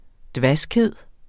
Udtale [ ˈdvasgˌheðˀ ]